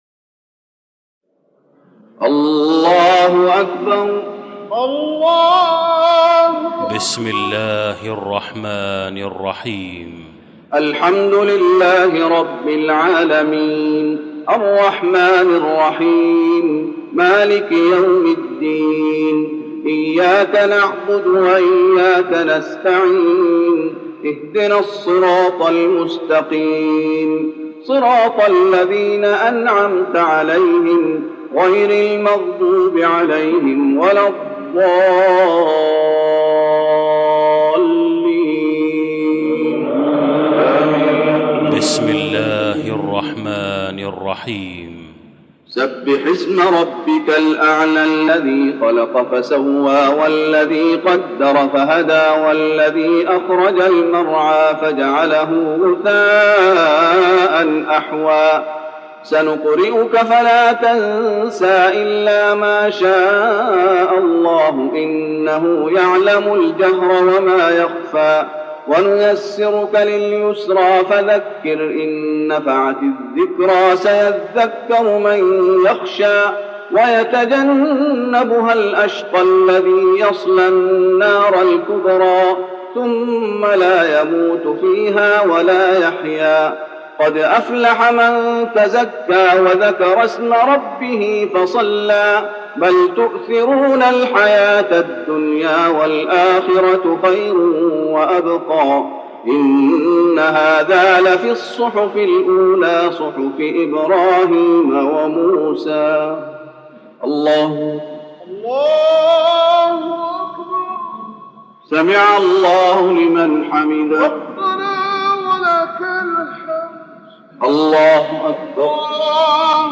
صلاة الوتر مع الدعاء للشيخ محمد أيوب رحمه الله > تراويح الشيخ محمد أيوب بالنبوي 1413 🕌 > التراويح - تلاوات الحرمين